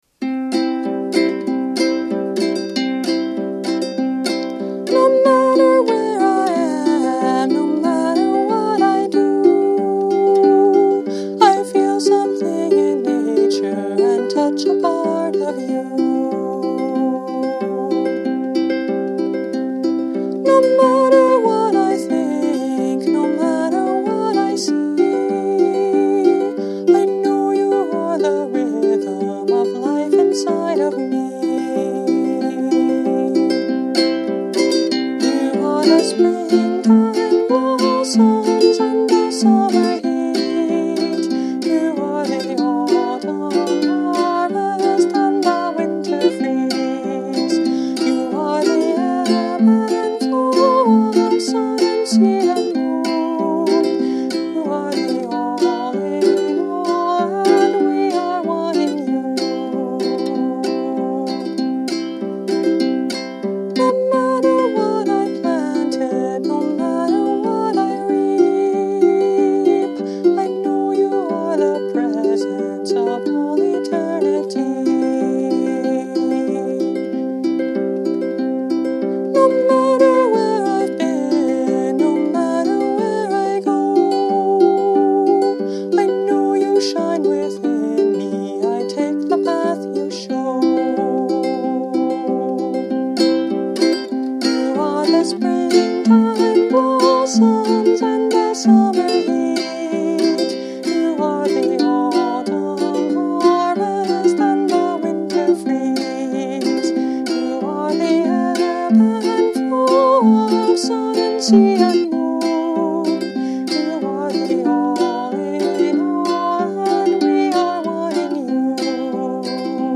And bluegrass on a uke?
Mahogany Concert Ukulele